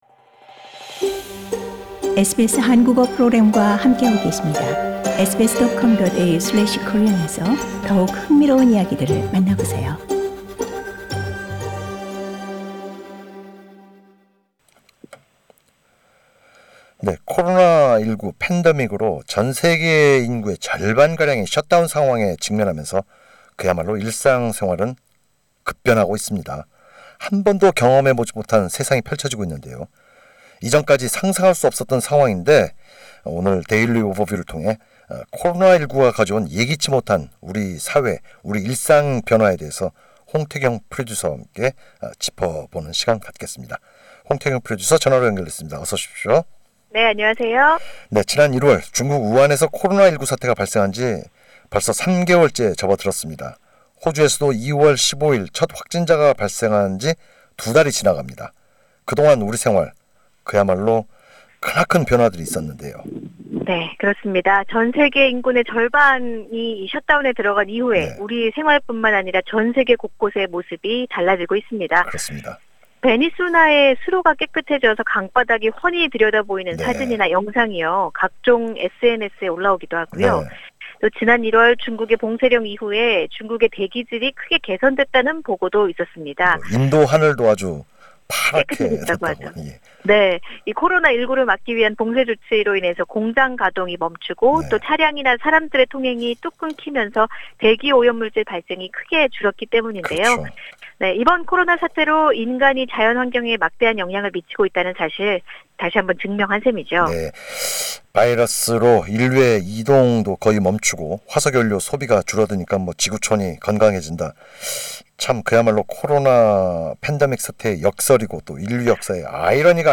전화 연결돼 있습니다.